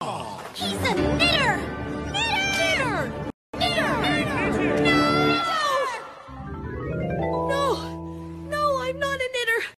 The video sounds so weird in reverse